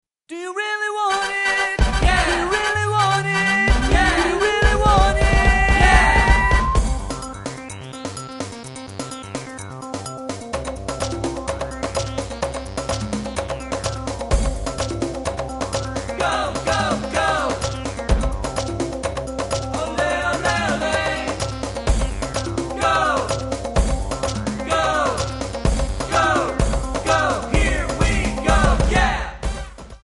Audio Backing tracks in archive: 9793
Buy With Backing Vocals.
Buy With Lead vocal (to learn the song).